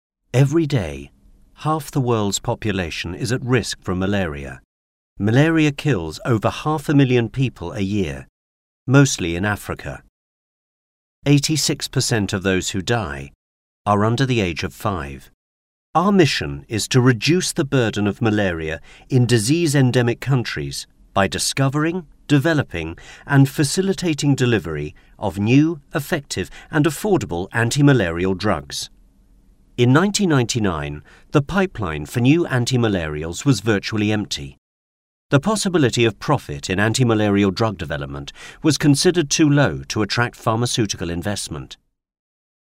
Playing age: 30 - 40s, 40 - 50s, 50 - 60s, 60+Native Accent: RPOther Accents: American, Estuary, Irish, London, Neutral, Northern, RP, Scottish, West Country, Yorkshire
• Native Accent: RP
• Home Studio